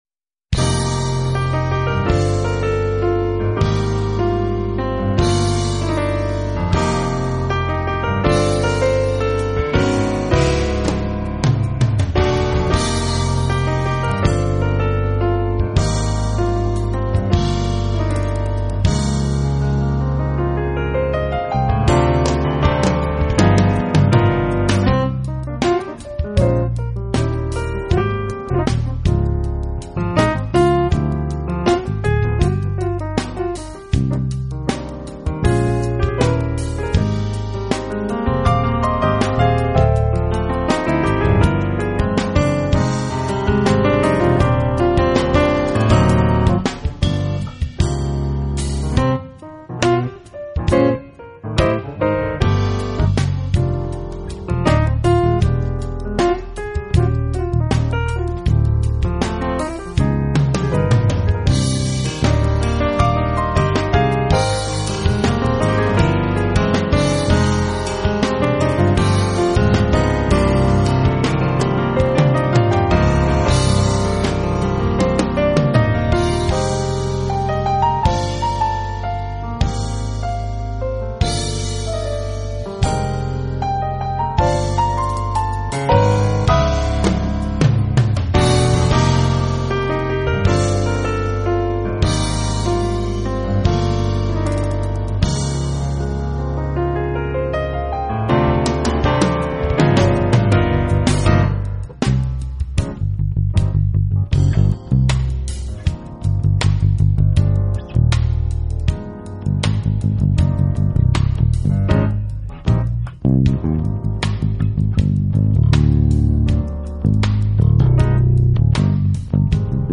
鋼琴
制大部分仍以原音三重奏為主